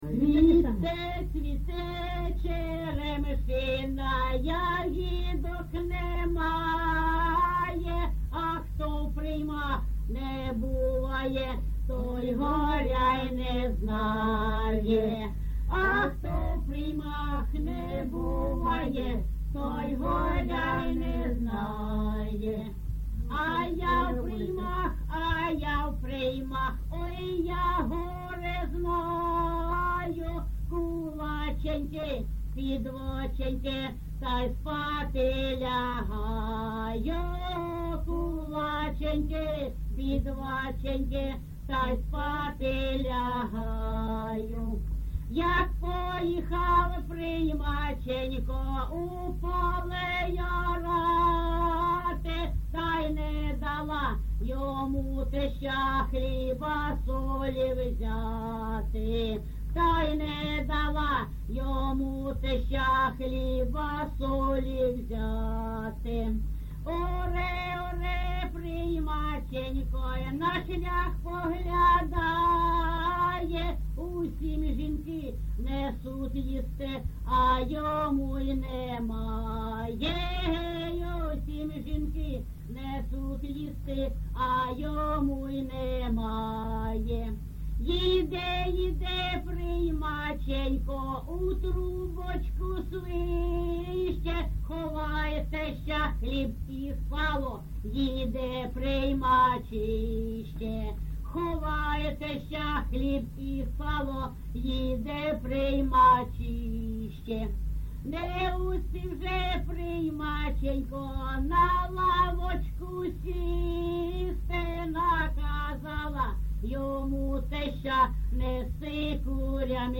ЖанрПісні з особистого та родинного життя
Місце записус. Званівка, Бахмутський район, Донецька обл., Україна, Слобожанщина
(+невідомі жінки)